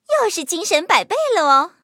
M2中坦补给语音.OGG